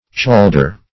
Search Result for " chalder" : The Collaborative International Dictionary of English v.0.48: Chaldrich \Chal"drich\, Chalder \Chal"der\, n. [Icel. tjaldr.]